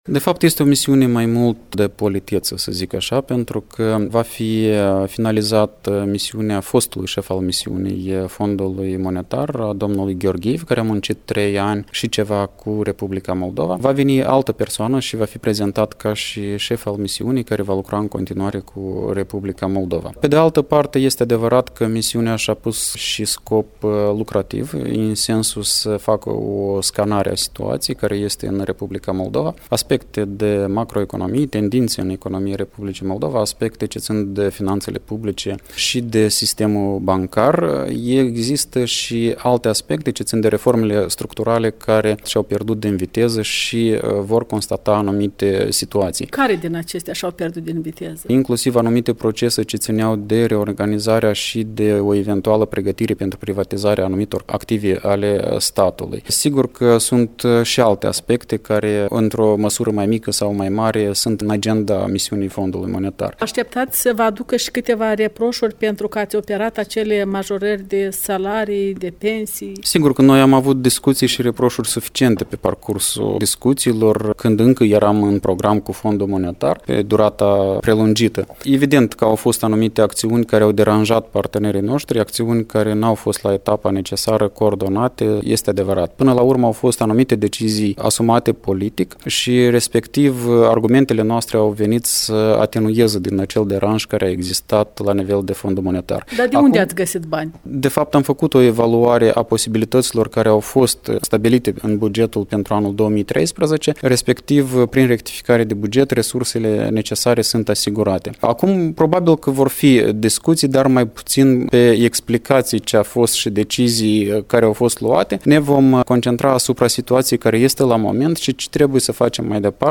Ministrul finanţelor, Veaceslav Negruţa, într-un interviu